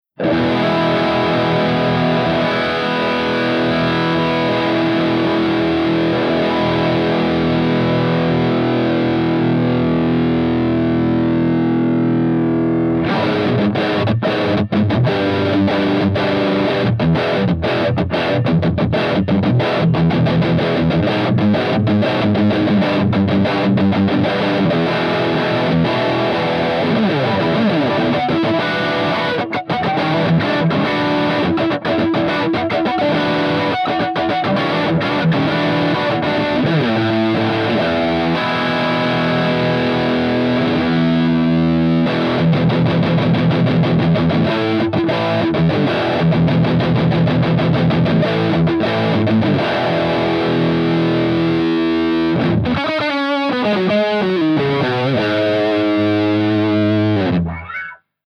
141_ROCKERVERB_CH2HIGHGAIN_V30_SC